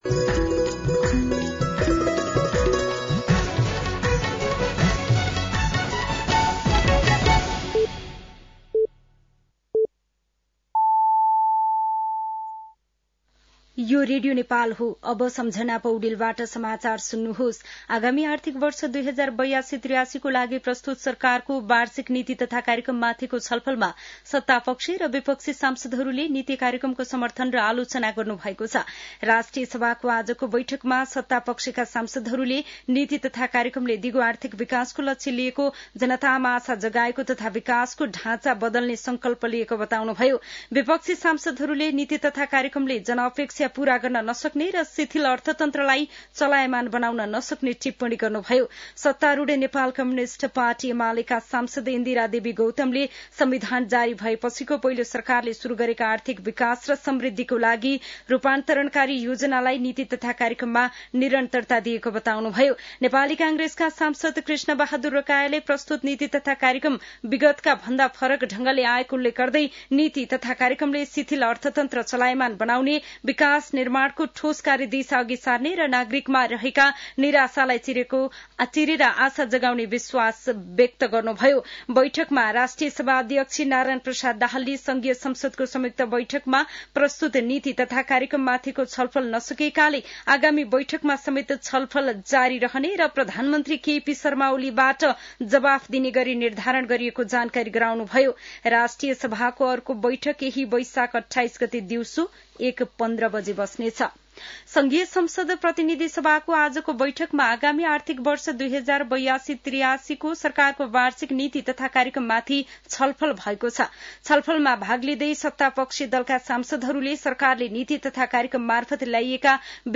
An online outlet of Nepal's national radio broadcaster
साँझ ५ बजेको नेपाली समाचार : २३ वैशाख , २०८२